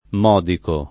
modico [ m 0 diko ]